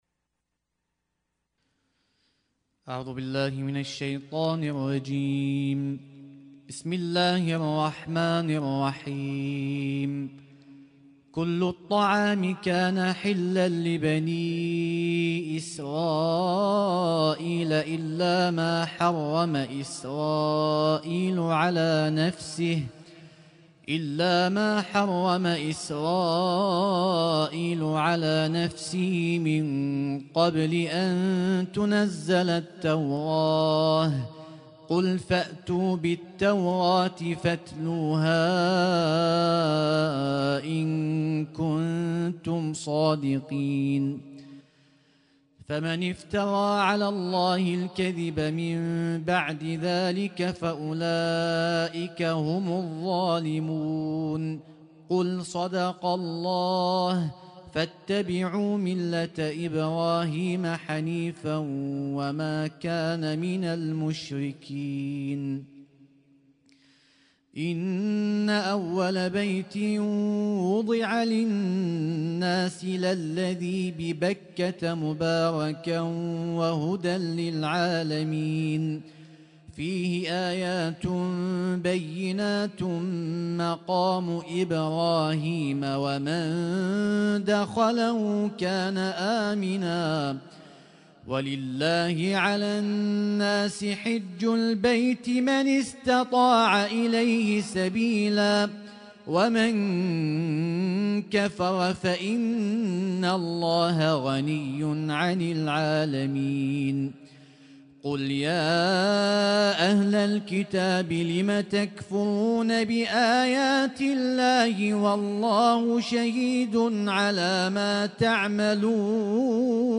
Husainyt Alnoor Rumaithiya Kuwait
اسم التصنيف: المـكتبة الصــوتيه >> القرآن الكريم >> القرآن الكريم - شهر رمضان 1446